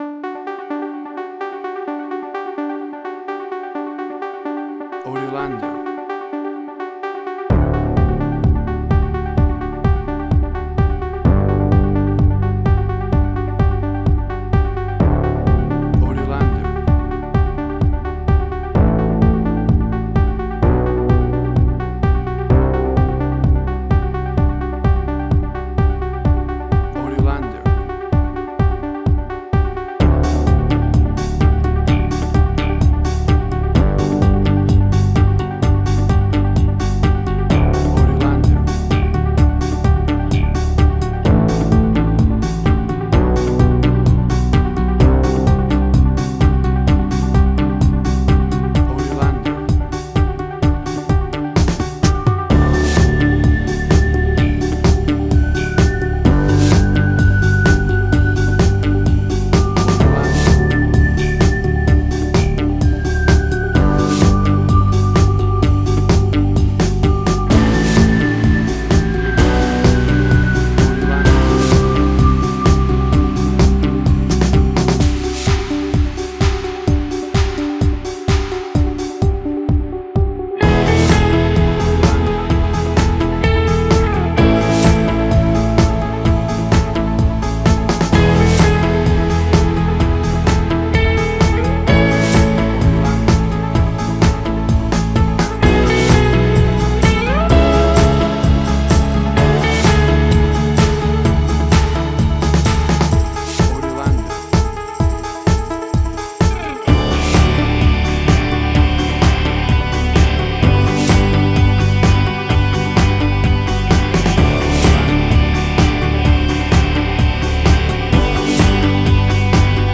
Suspense, Drama, Quirky, Emotional.
Tempo (BPM): 128